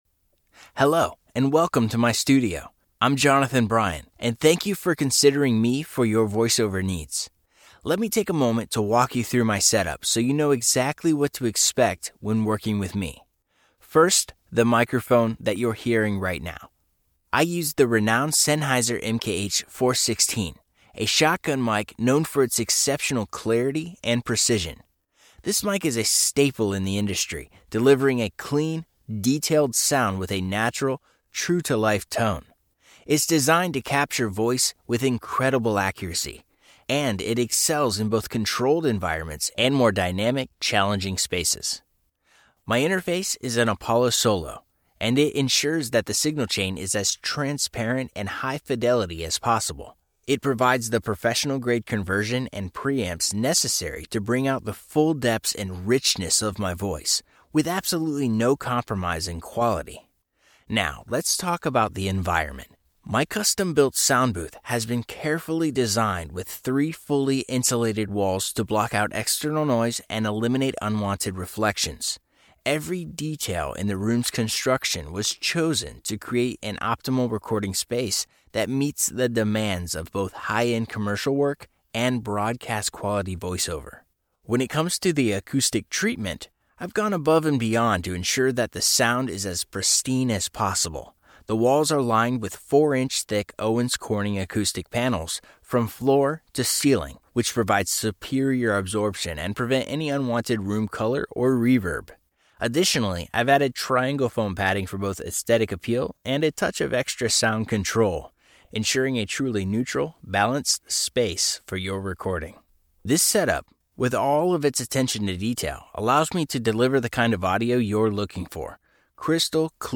voz de barítono dinámica y áspera con un estilo auténtico y conversacional, ideal para anuncios, narraciones y personajes.
Tengo un estudio de grabación profesional en casa, así como sólidas habilidades de mezcla y masterización.